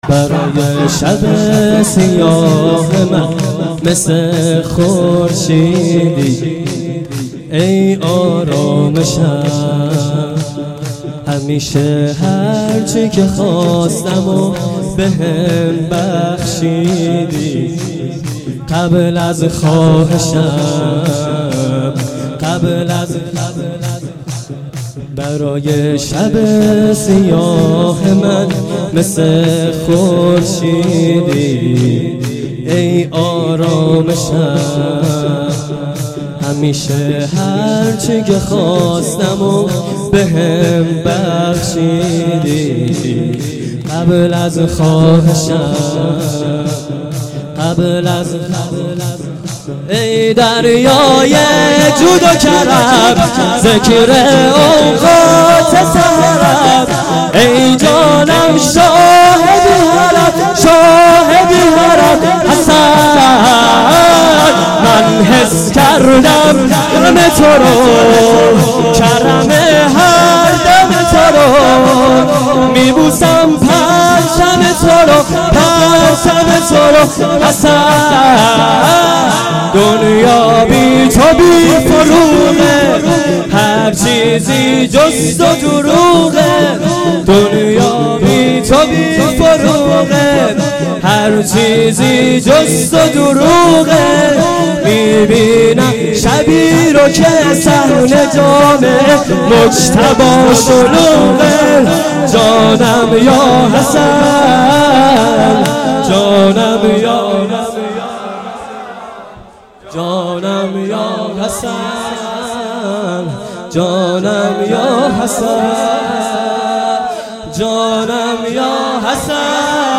شور شب پنجم فاطمیه